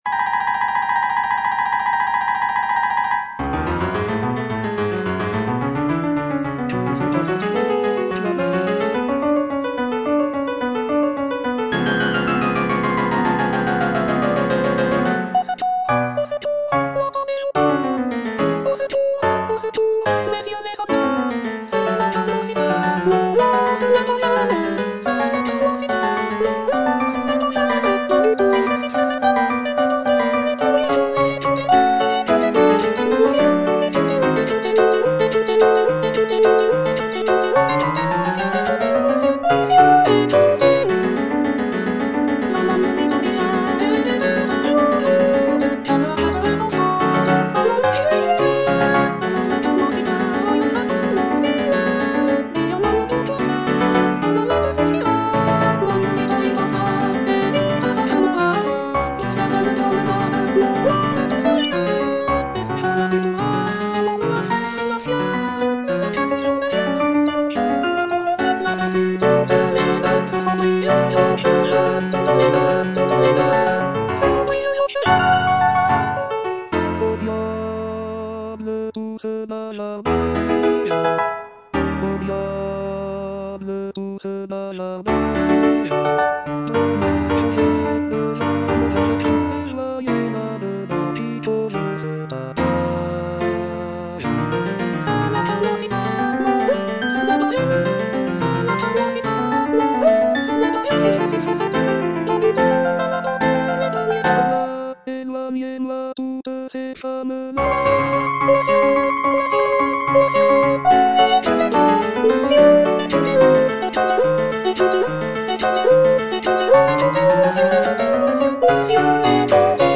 G. BIZET - CHOIRS FROM "CARMEN" Que se passe-t-il donc là-bas? (All voices) Ads stop:
It is a vibrant and dramatic work, featuring several powerful choral moments.